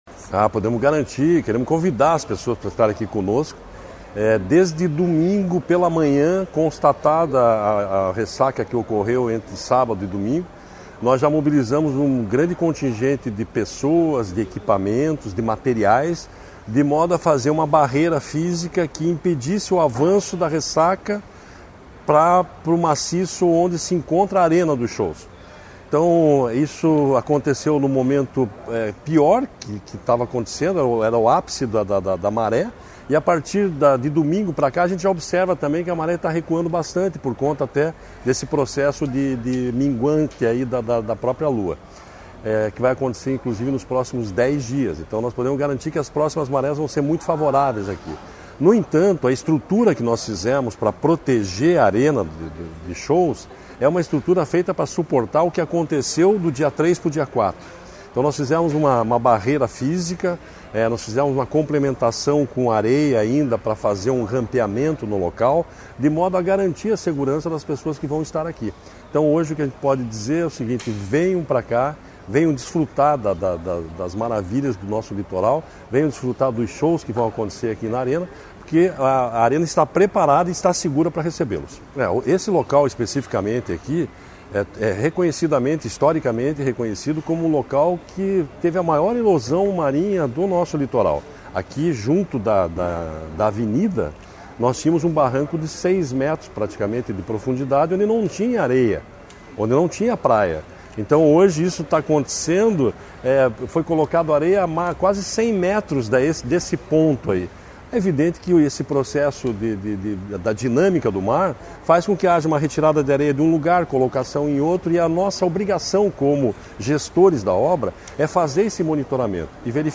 Sonora do diretor-presidente do IAT, Everton Souza, sobre a manutenção dos shows do Verão Maior em Caiobá